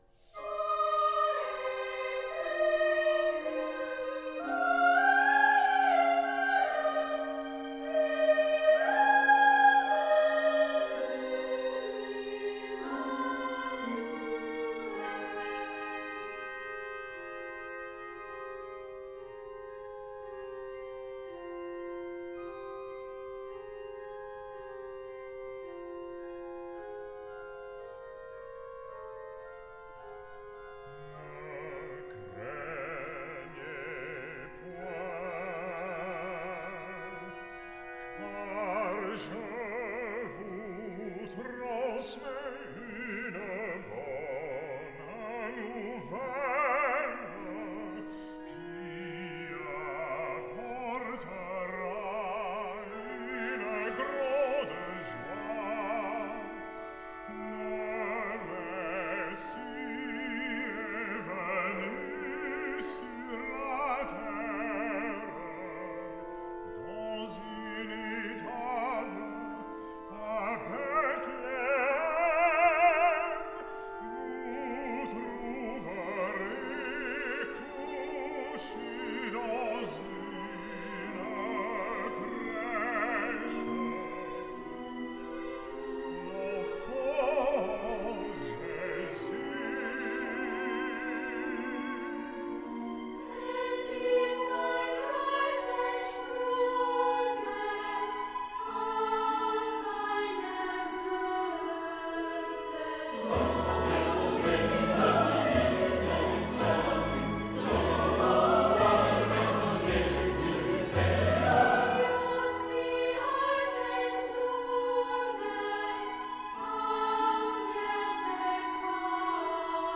The BBC National Orchestra and Chorus of Wales. Thierry Fischer, conductor.